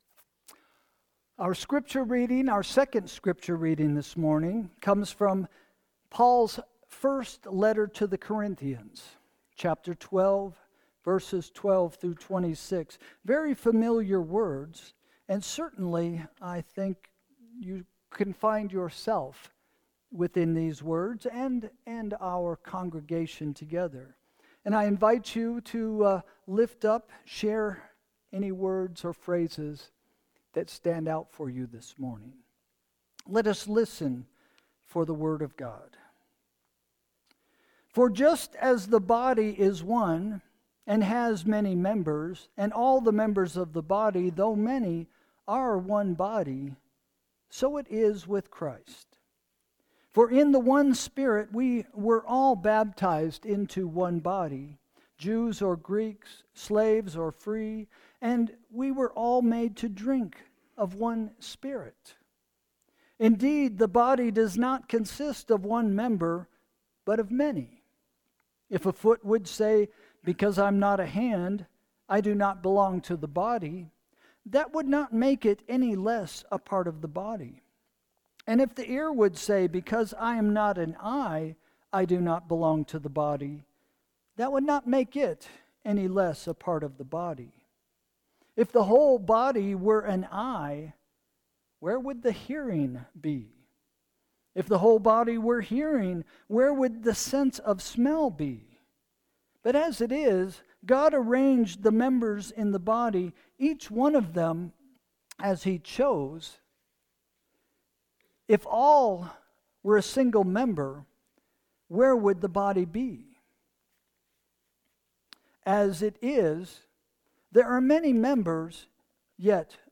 Sermon – August 10, 2025 – “See Jesus” – First Christian Church